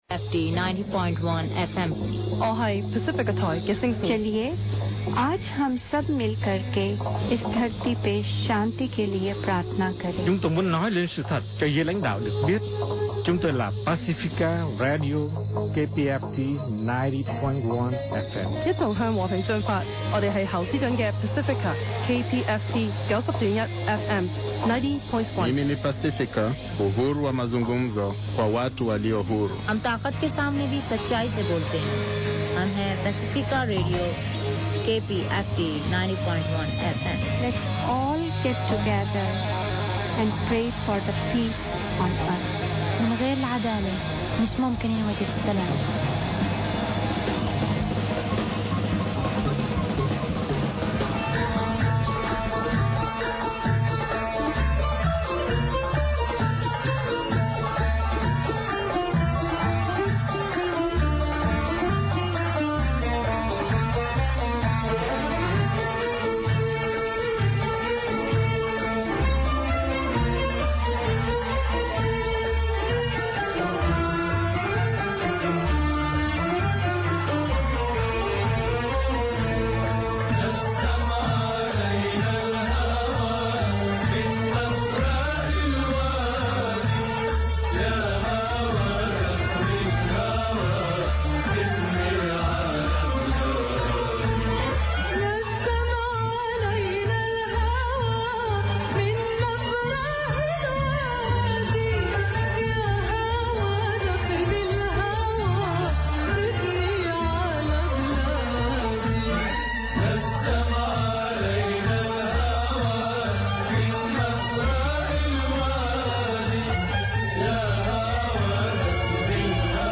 Arab Voices Radio Talk Show - 2007 Archives
Tune in and listen to Arab Voices for the latest news, views and live discussions about the Middle East.